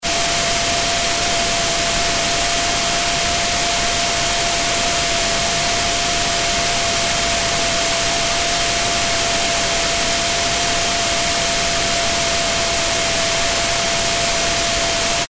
Normalized the noise because my hearing threshold is WAY below all of yous chaps!